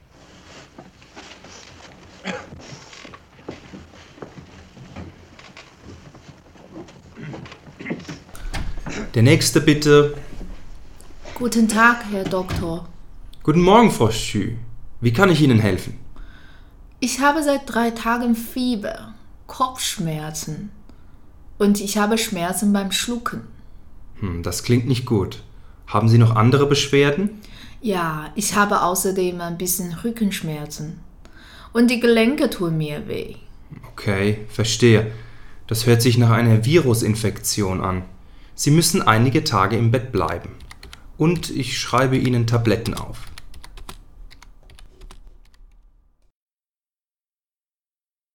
Aktivität 2   Hören von Gesprächen zwischen Arzt und Patienten
Gespräch 1